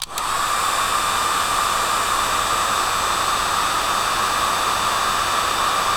DRYER   1 -S.WAV